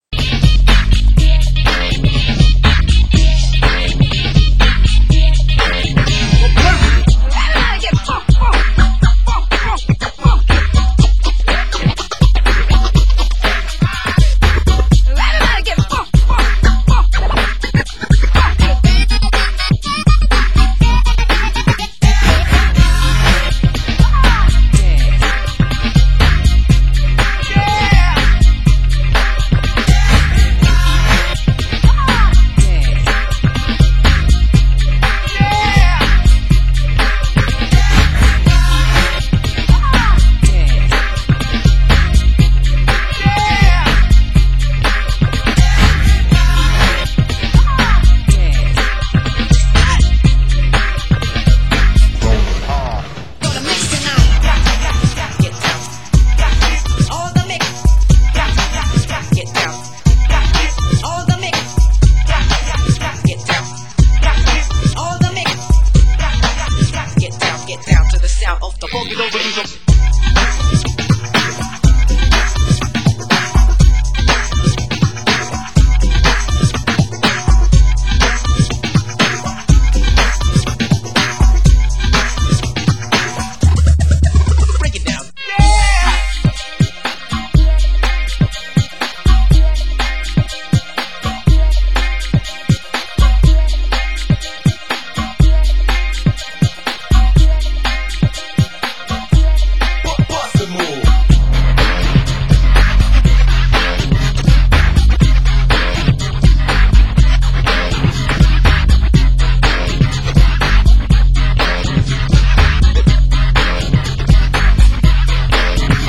Genre: Hip Hop